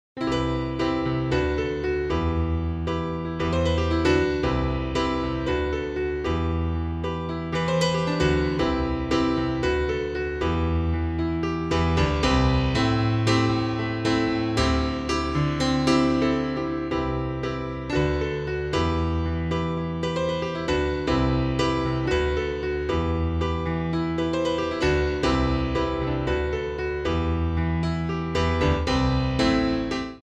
--> MP3 Demo abspielen...
Tonart:G Multifile (kein Sofortdownload.
Die besten Playbacks Instrumentals und Karaoke Versionen .